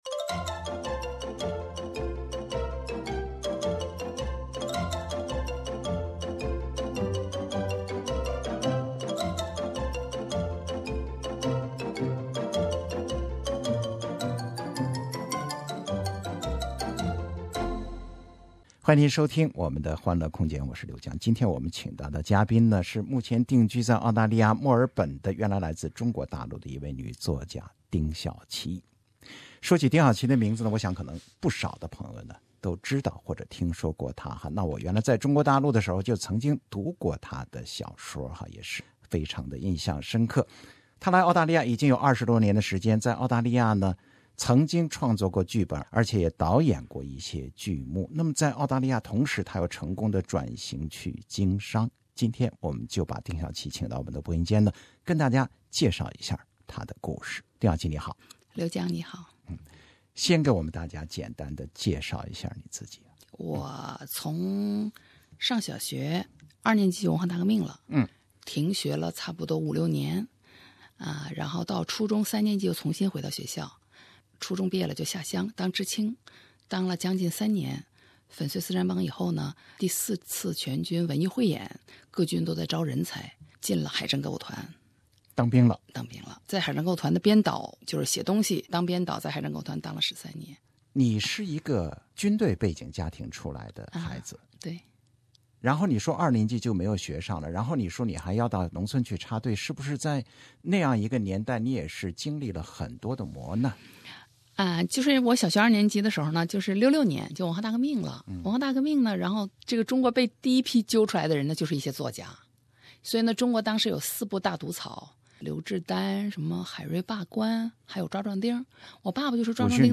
唱她年轻时创作的曾经几乎响遍中国大江南北的知识青年歌曲。